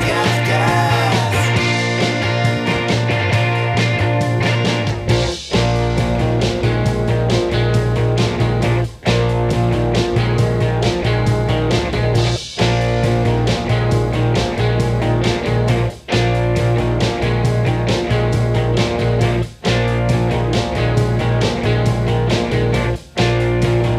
One Semitone Down Rock 3:20 Buy £1.50